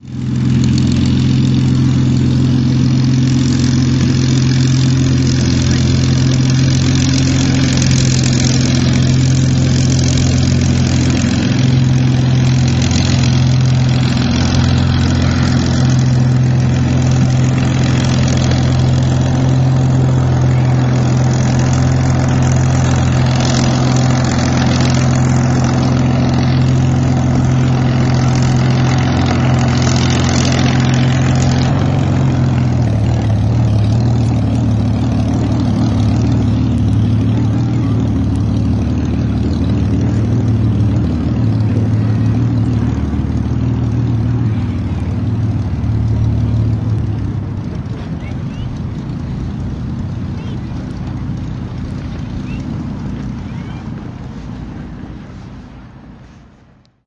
二战飞机军事表演
描述：在一场二战战斗机军事表演上记录，飞机经过两次。
标签： 战斗机 飞机 二战 表演
声道立体声